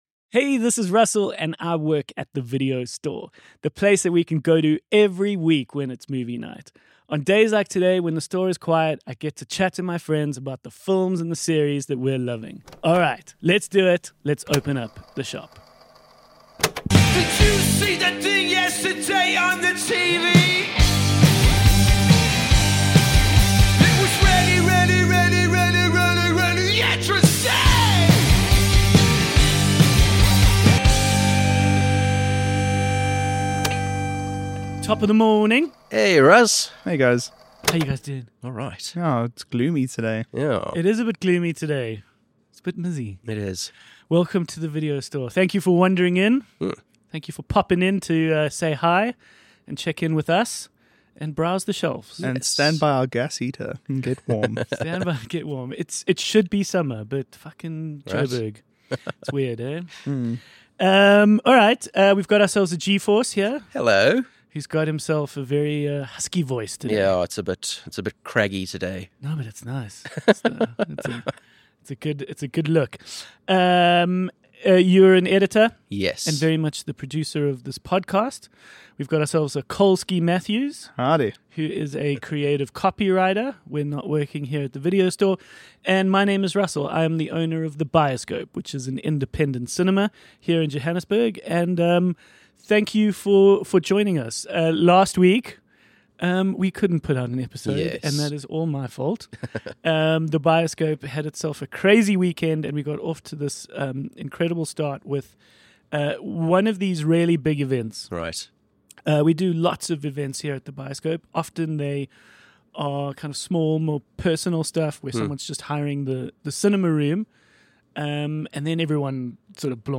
This week's guest is author, actor and producer John van de Ruit.